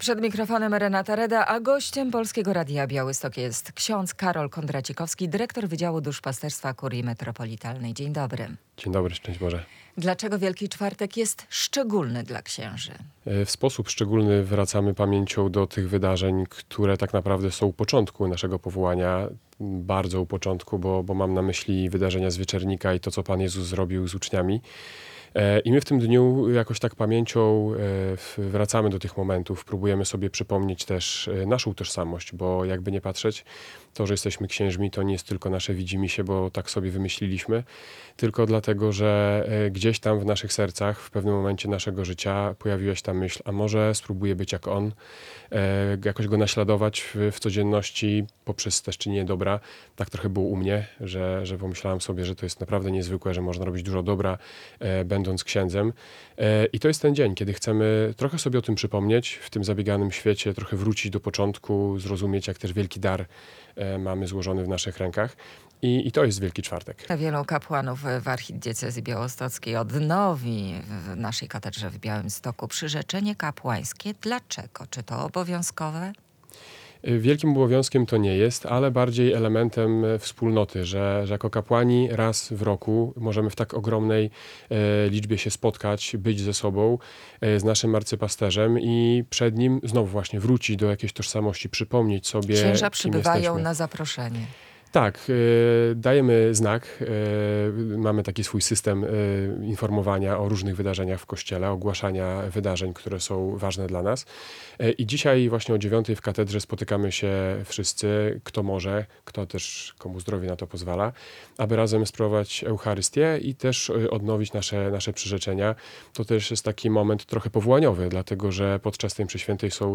Wielki Czwartek - powrót do początku kapłaństwa